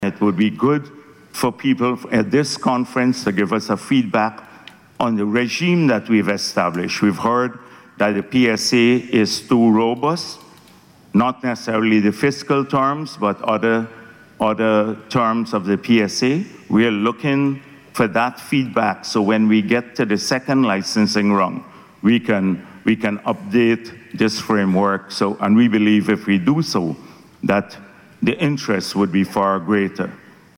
Day Two of the Energy Conference saw Vice President Dr. Bharrat Jagdeo reaffirming Guyana’s commitment to ensuring the safety of investments in its burgeoning oil and gas industry.